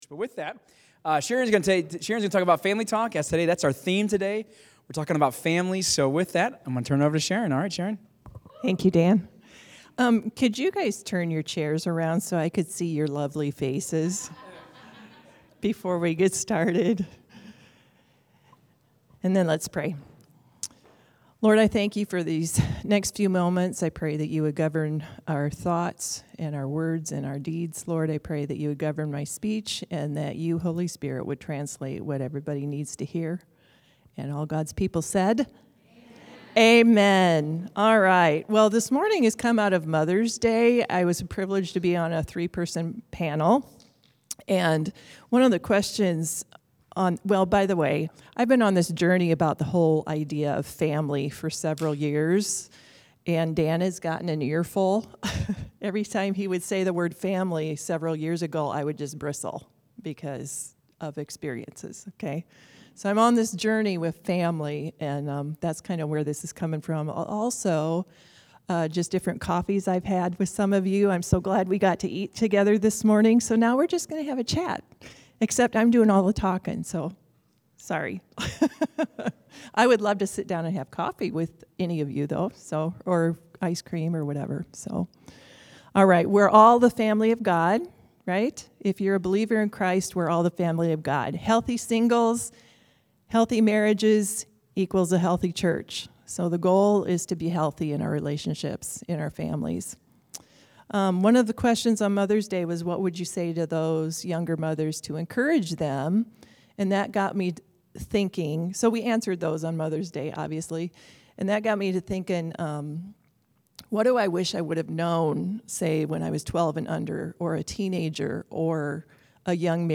Sermons | Crosspointe Church